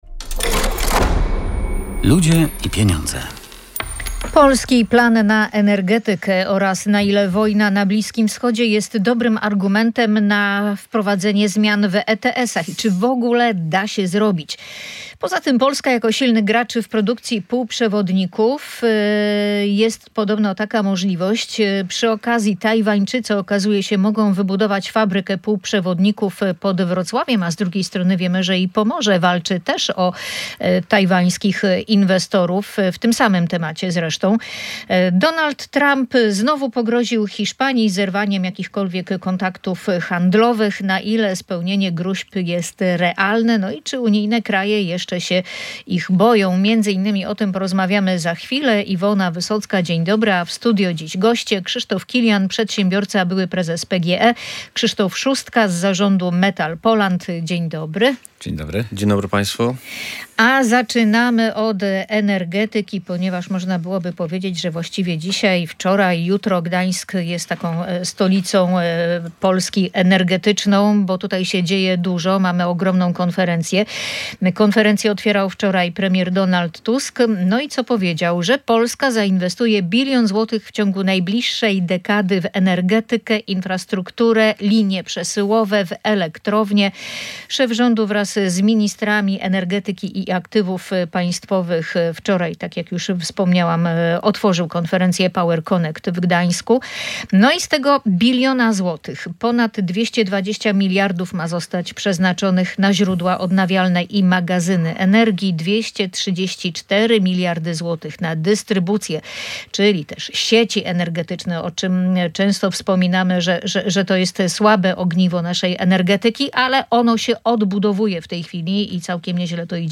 Na ile wojna na Bliskim Wschodzie jest dobrym argumentem na wprowadzenie zmian w ETS? Czy da się to zrobić? Na ten temat rozmawiali goście audycji „Ludzie i Pieniądze”